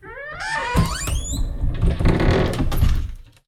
wardrobe2.wav